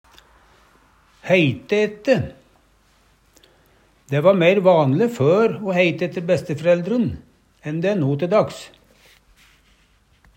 heite ette - Numedalsmål (en-US)